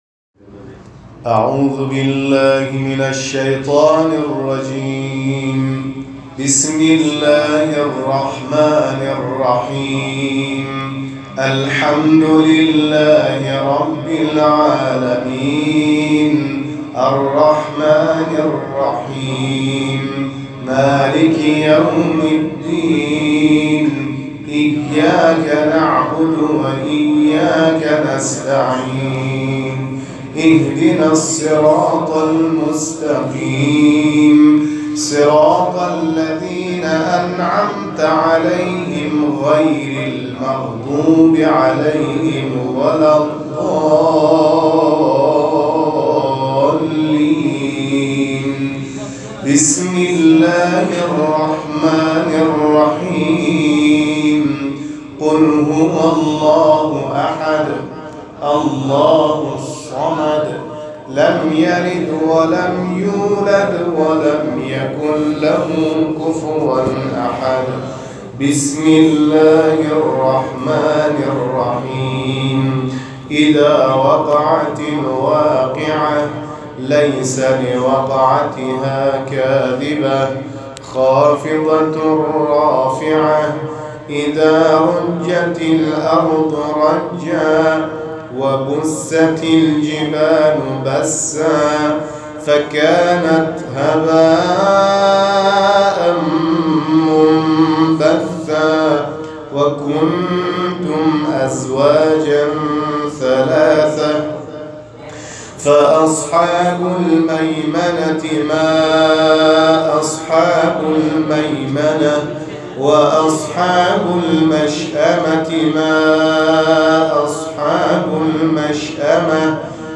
اجرای تلاوت ترتیل کاروان قرآنی نور در بین زائران ایرانی + عکس
گروه فعالیت‌های قرآنی ــ کاروان قرآنی نور اعزامی به مراسم حج سال 97 با حضور در هتل‌های محل اقامت زائران ایرانی، به اجرای برنامه تلاوت ترتیل می‌پردازند.